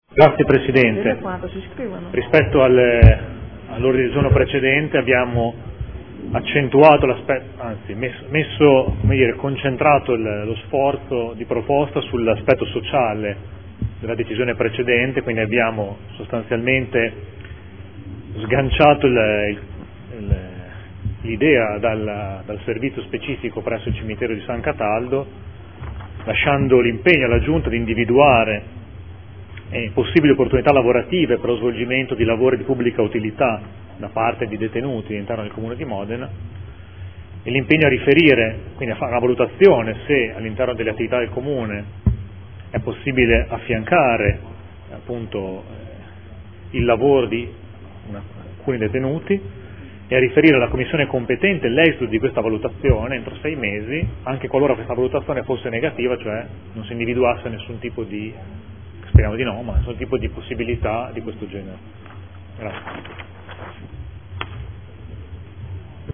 Seduta del 05/03/2015 Ritira ordine del giorno Prot. 28648 e presenta odg Prot. 29386